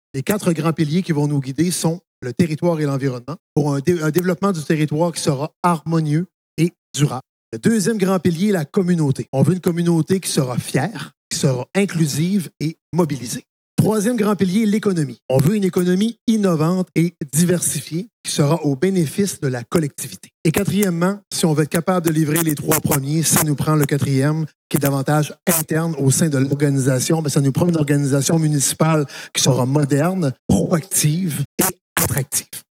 En tout, cette planification identifie quatorze grandes priorités, qui seront déclinées en 65 chantiers concrets. À nouveau, Daniel Côté :